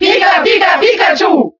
Category:Crowd cheers (SSBB) You cannot overwrite this file.
Pikachu_Cheer_German_SSBB.ogg